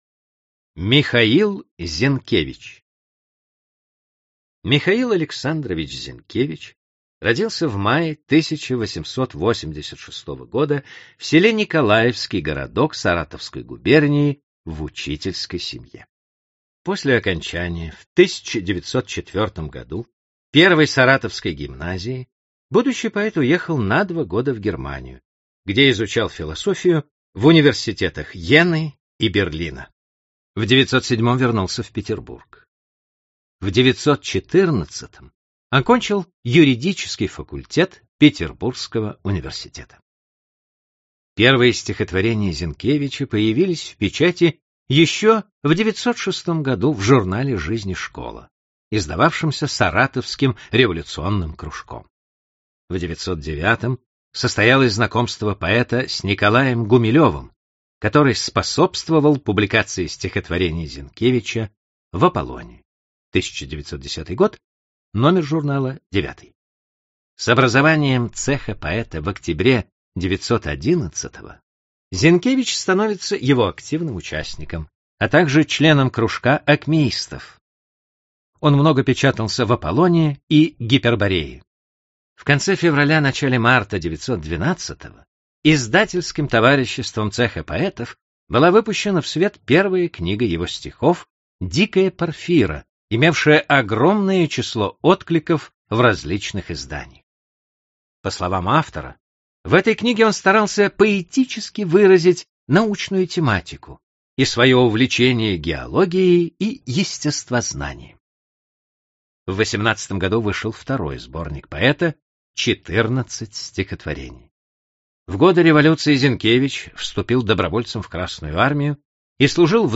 Аудиокнига Великие имена Серебряного века | Библиотека аудиокниг